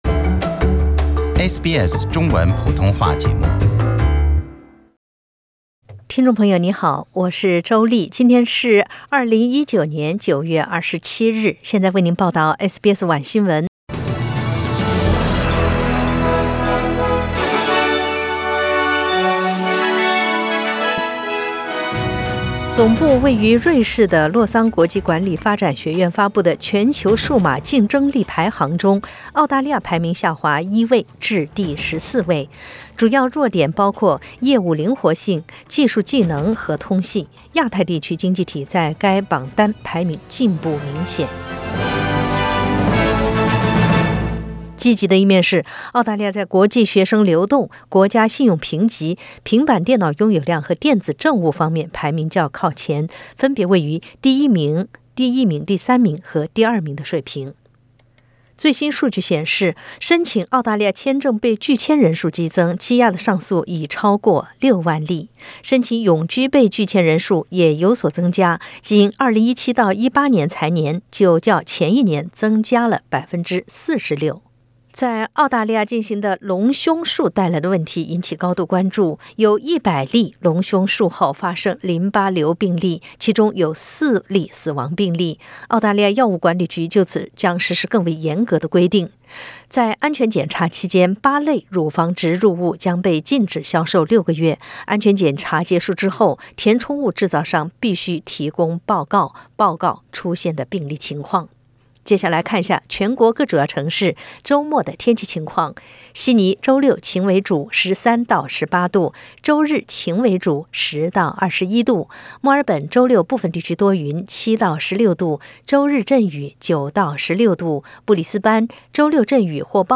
SBS 晚新闻 （9月27日）